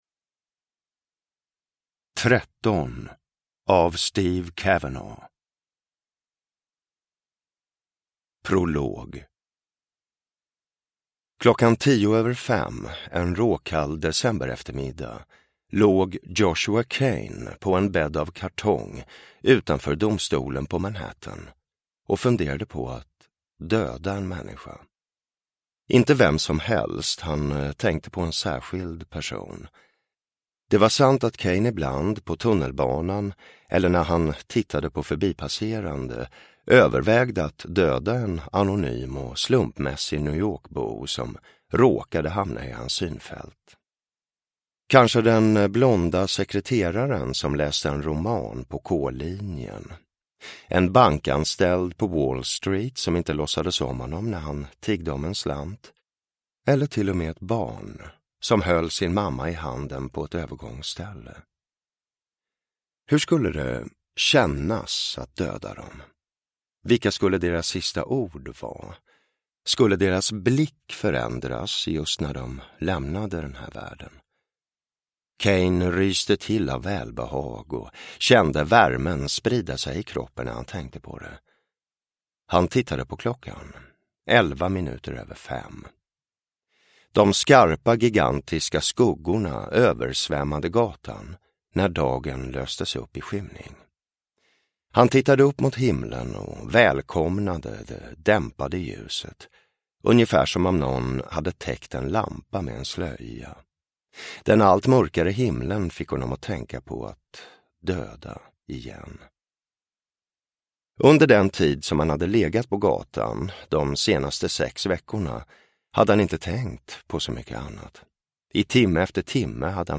Tretton (ljudbok) av Steve Cavanagh